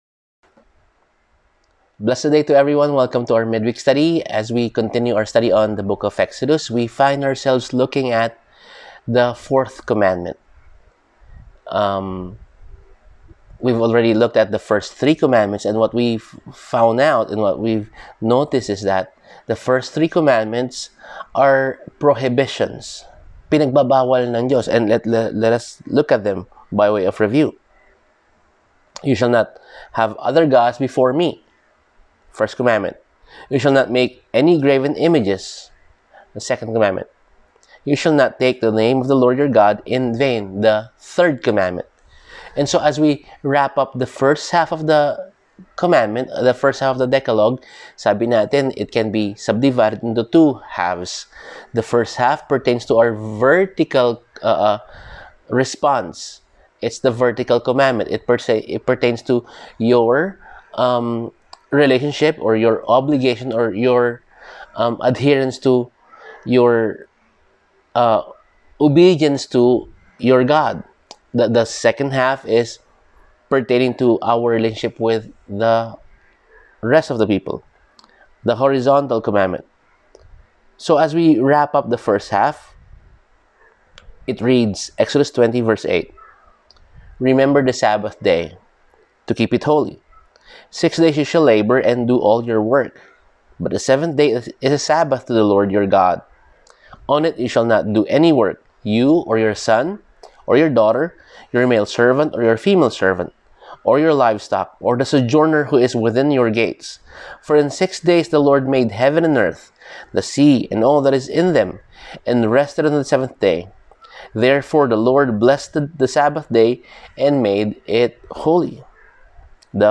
Service: Midweek Sermon